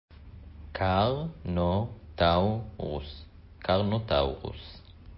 קר-נו-טאו-רוס